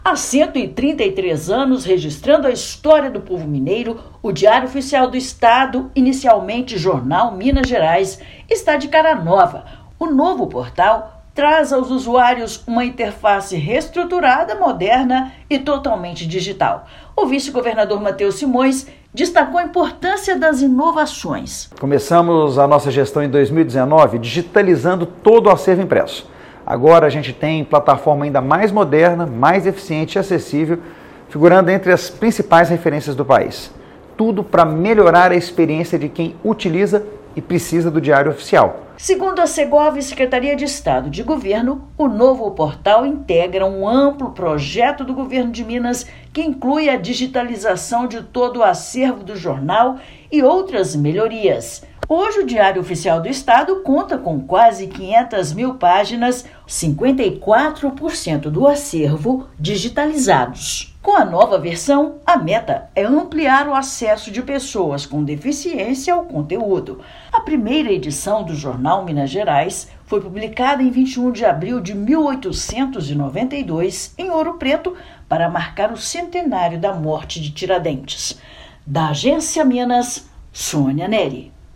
Com interface intuitiva, plataforma chega para ampliar a transparência e o acesso à informação. Ouça matéria de rádio.